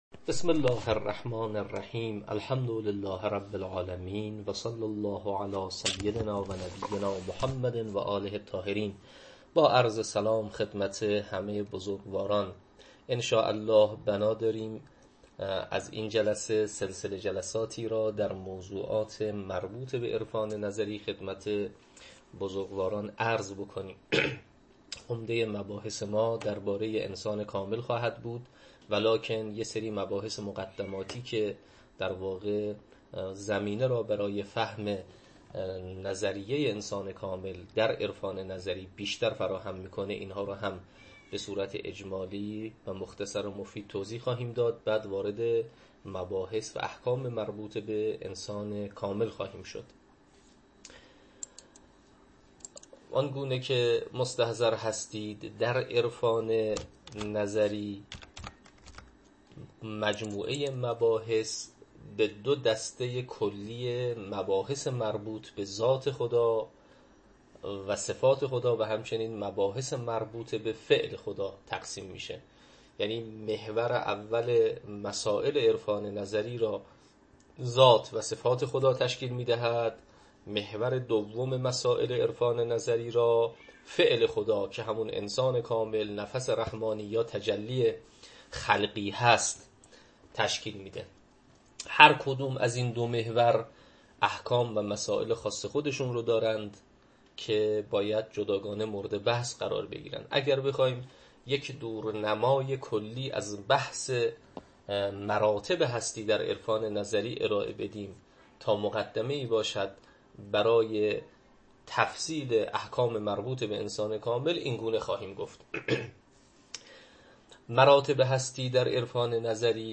تدریس عرفان نظری